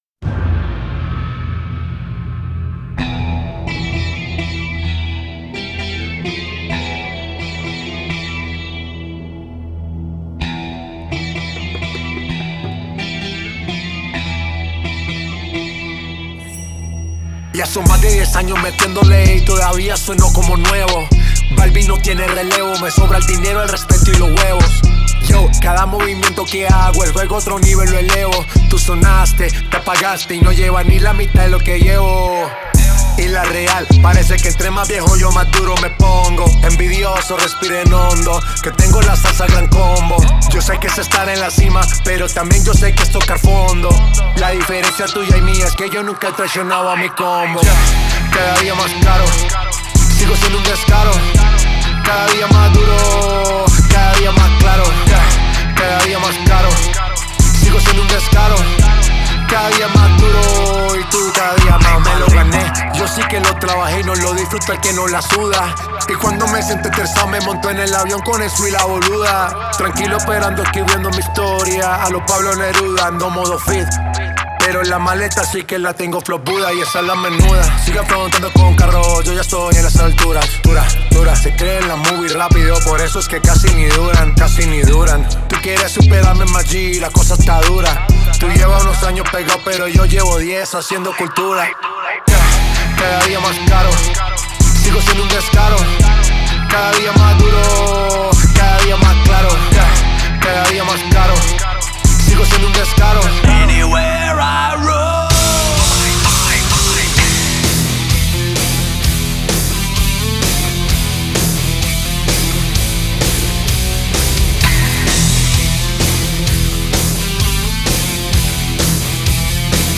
ритмичными битами